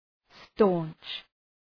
Προφορά
{stɒntʃ}